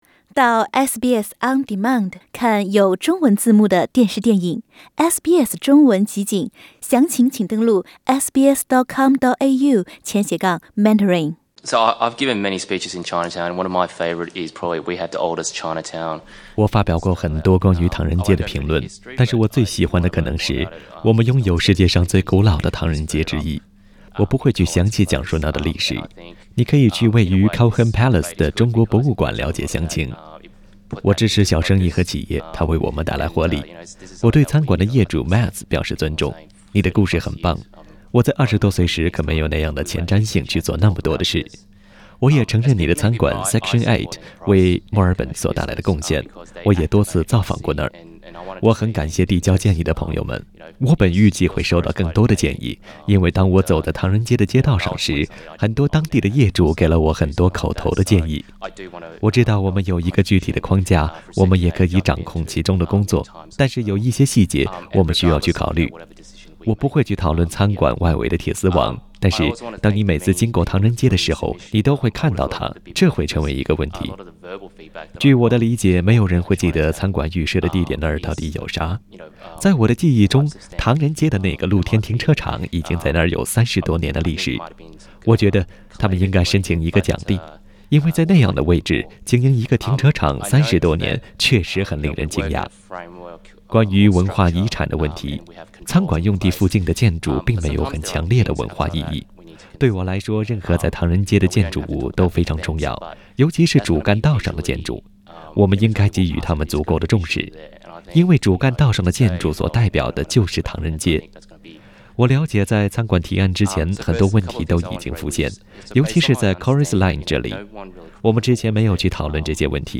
我们先来听听市议员刘乐的评论。